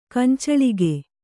♪ kancaḷige